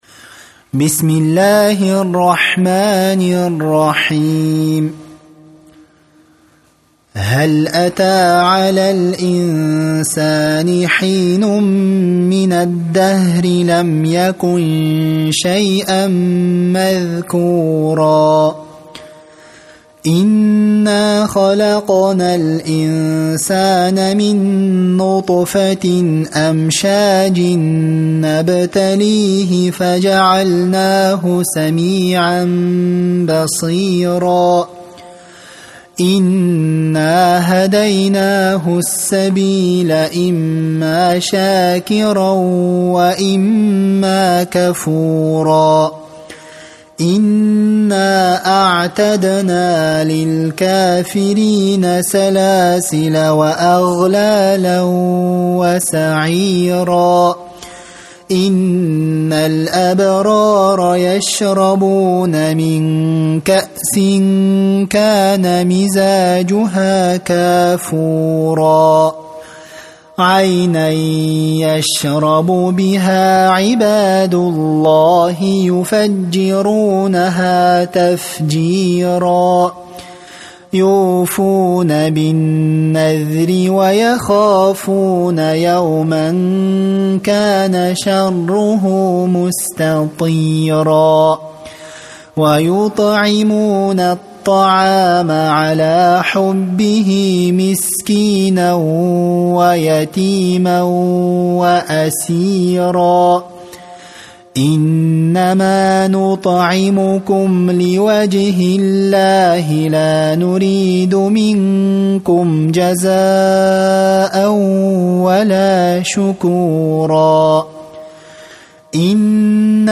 Ассаляму алейкум уа рахматуЛлахи уа баракатух! маша Аллах, ариптери оте анык естиледи екен, жаттаганга оте ынгайлы))) Осы кисинин кырагатымен дугаларды да алсак нур устине нур болар еди, мумкин болар ма екен?